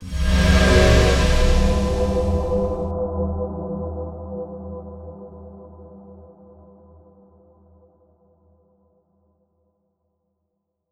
Glyph Activation Warm Aura.wav